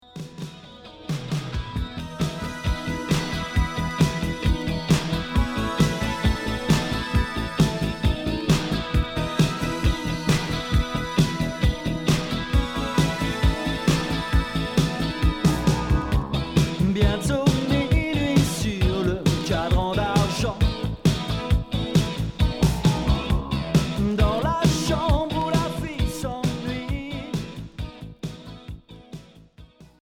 New wave Unique 45t retour à l'accueil